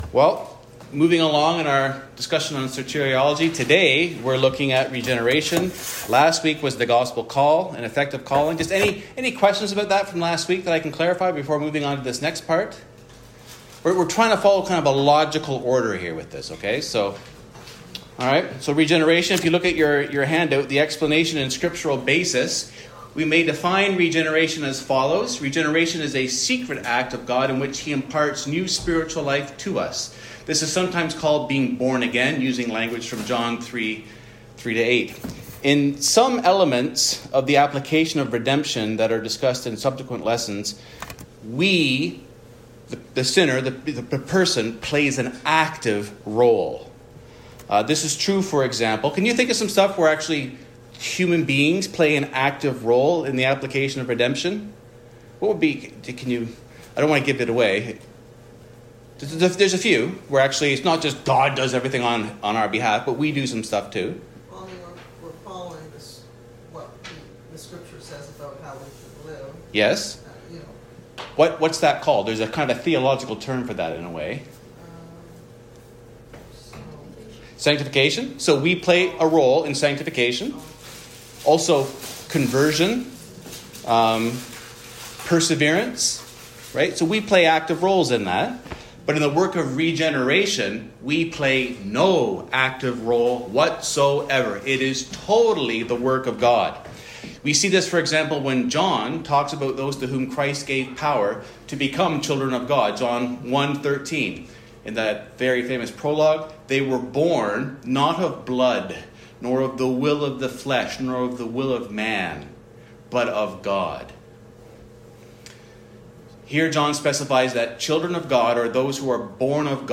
The sermons of Mount Pleasant Baptist Church in Toronto, Ontario.